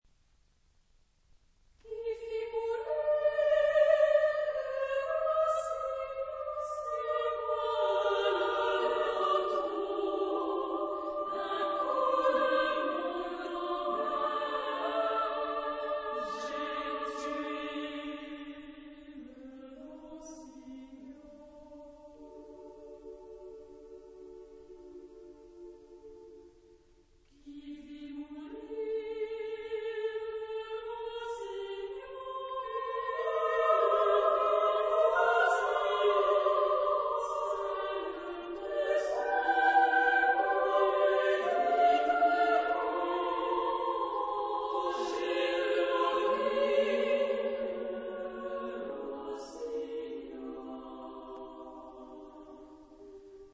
Epoque: 20th century
Genre-Style-Form: Contemporary ; Partsong ; Dance ; Secular
Type of Choir: SSA  (3 children OR women voices )